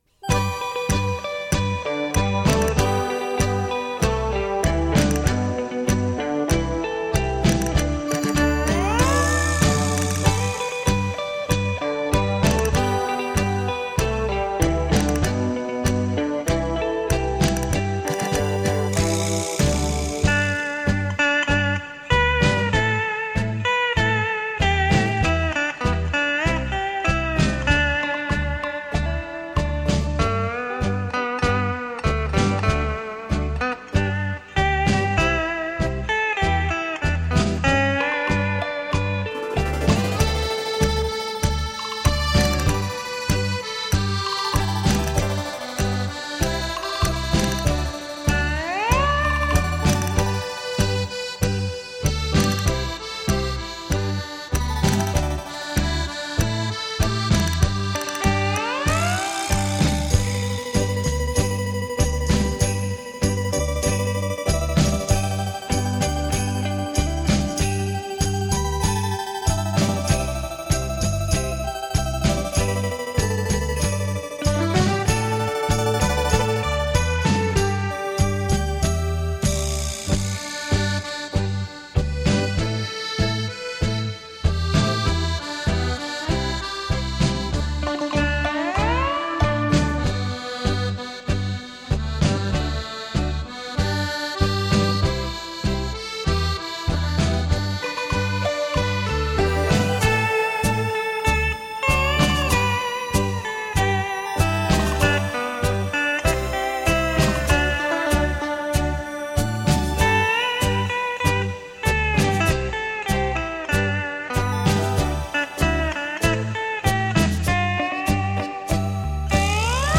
夏威夷吉他·女和音